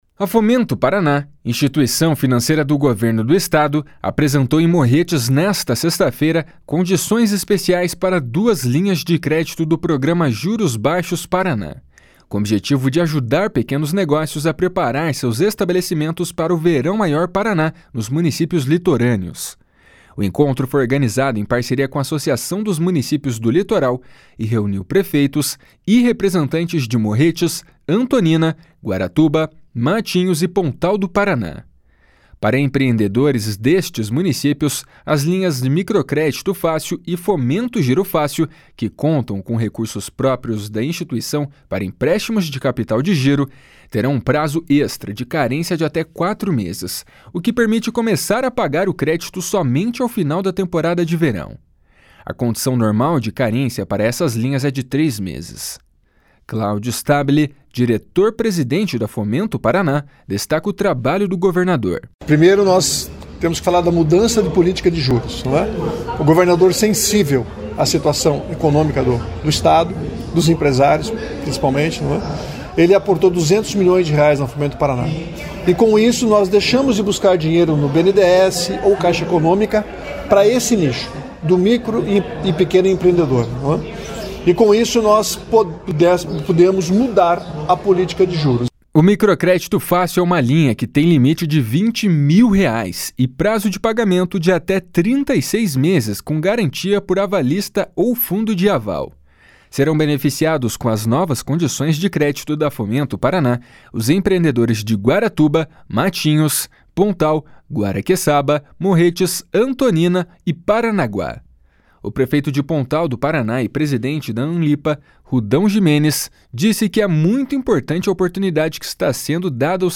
O prefeito de Pontal do Paraná e presidente da Amlipa, Rudão Gimenes, disse que é muito importante a oportunidade que está sendo dada ao setor privado.
Junior Brindaroli, prefeito de Morretes, elogiou a proposta da Fomento Paraná, que acompanha o trabalho de investimento do estado na região.